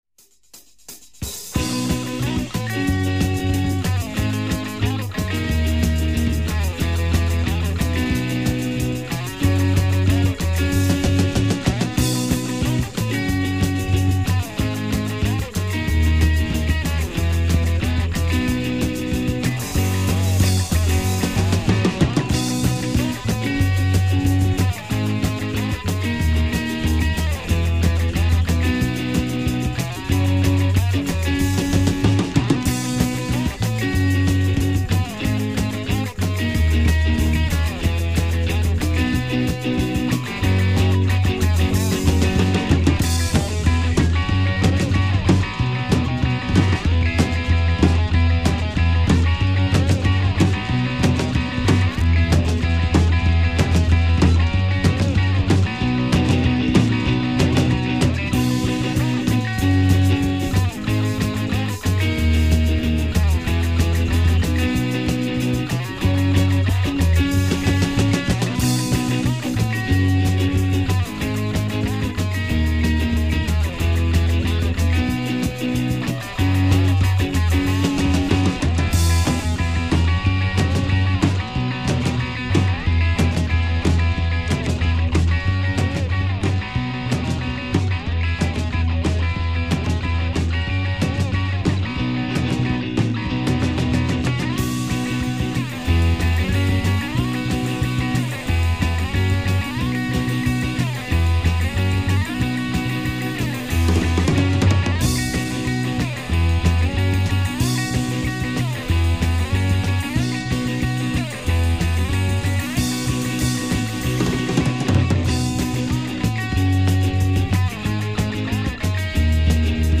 Guitar
Bass
Drums
Keyboards
Recorded in Blästadgården and at some basements in Vidingsjö